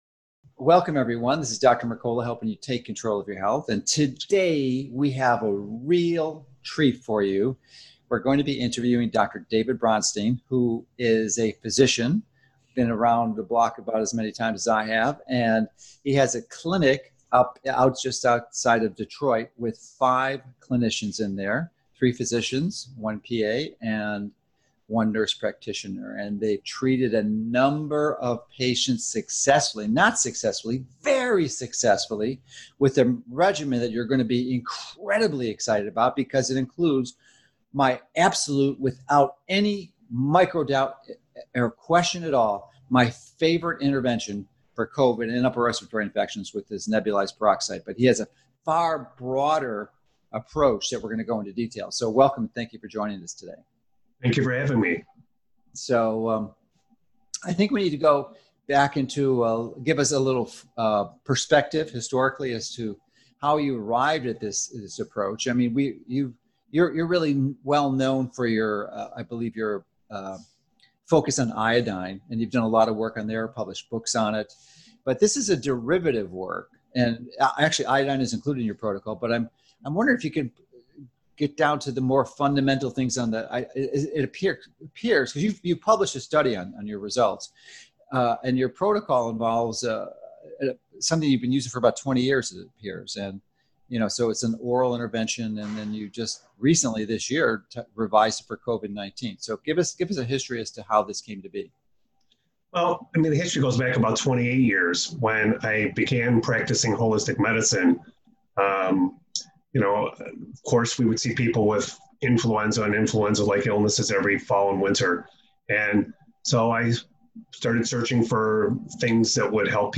Nebulized Peroxide- Interview